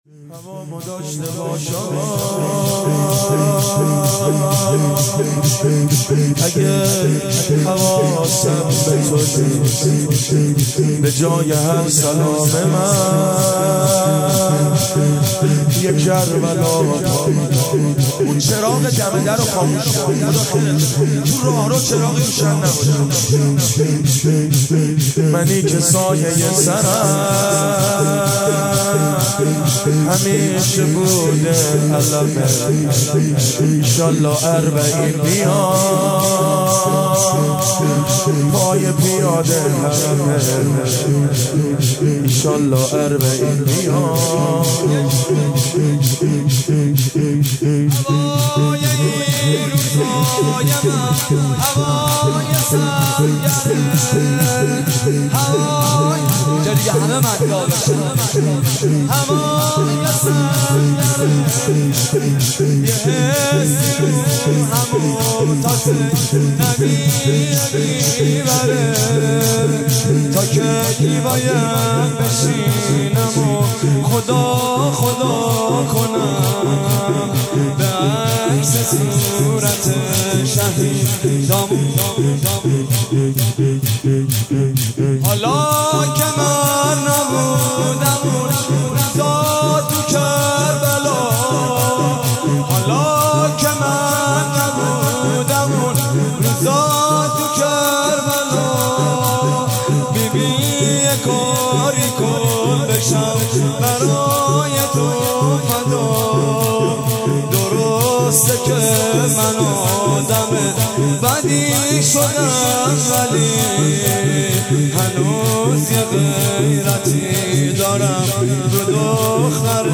روضه
شور